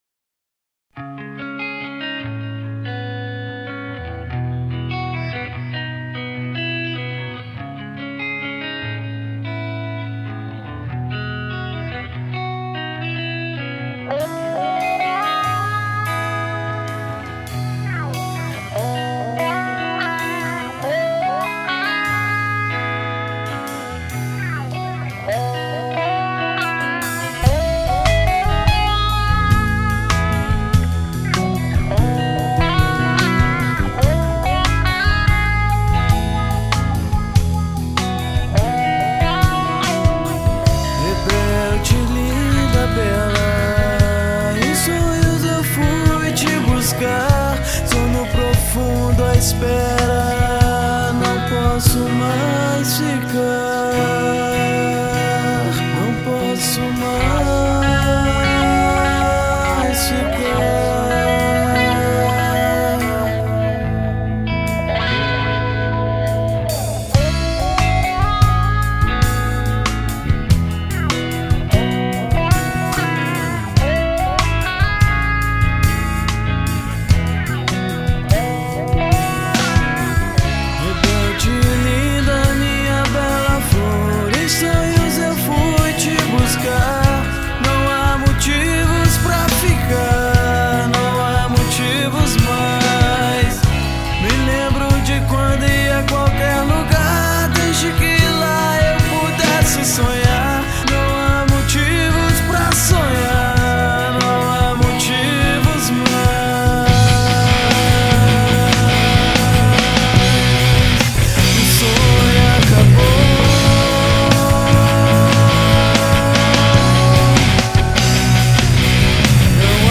EstiloGrunge